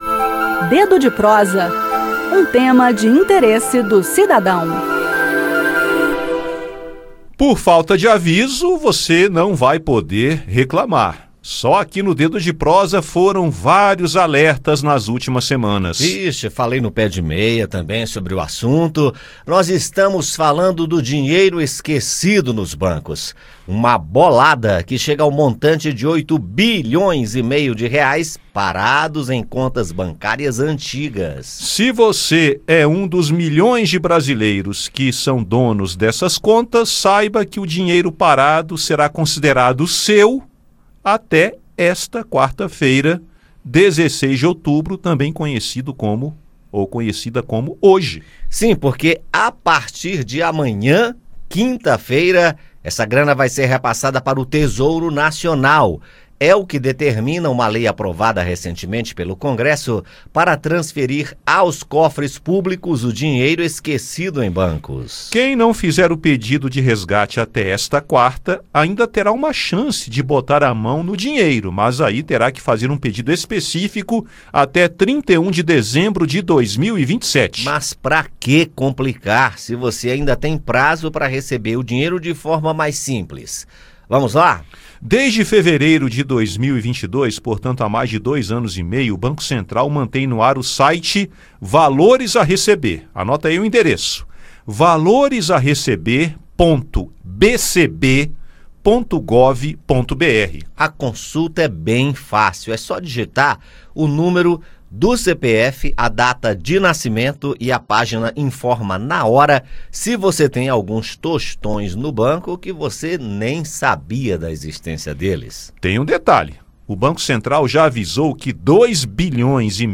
No bate-papo, saiba como acessar o Banco Central para verificar e resgatar.